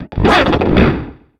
Cri d'Ouvrifier dans Pokémon X et Y.